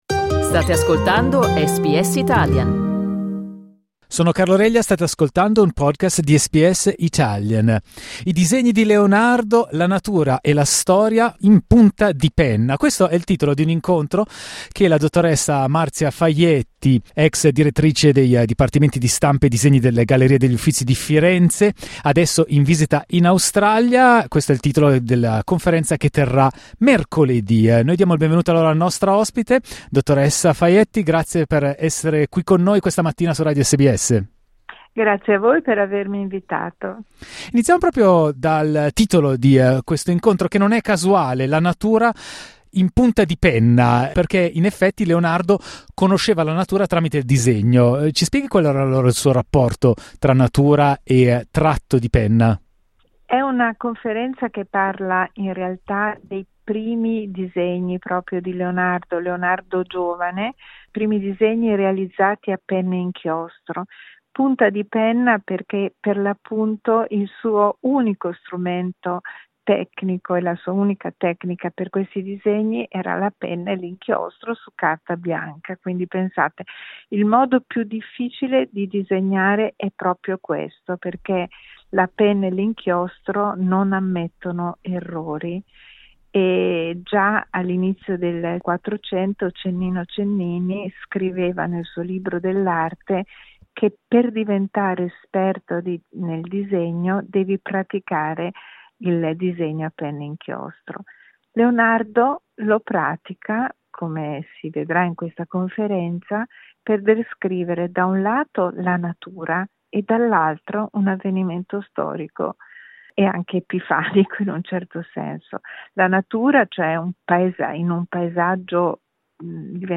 Al centro della sua analisi tre disegni: il celebre disegno per Santa Maria Della Neve ed i disegni preparatori per l'Adorazione dei Magi. Ascolta l'intervista